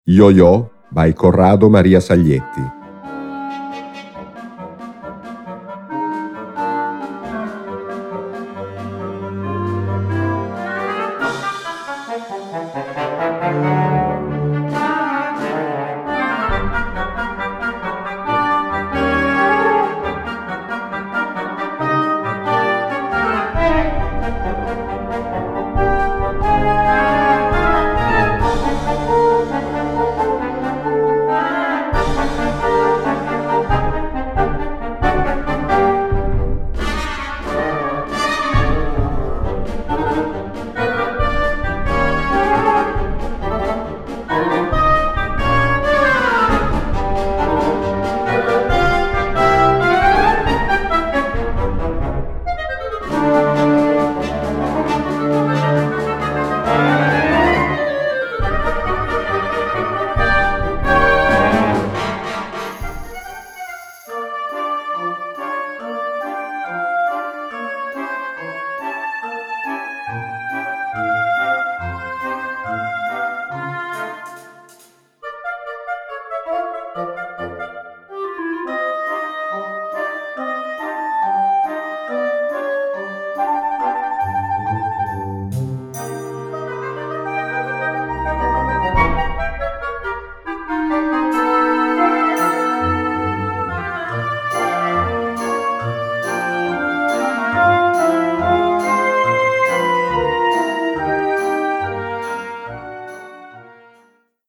Solo für Klarinette, Oboe, Horn oder Fagott
Besetzung: Blasorchester